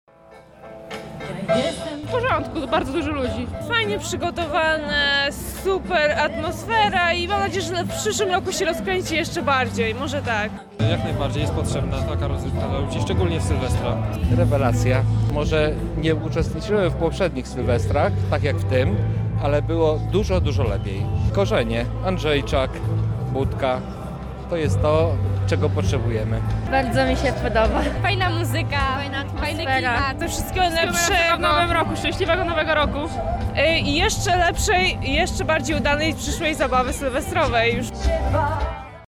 Mieszkańcy Lublina świętowali w ostatni dzień roku na Placu Teatralnym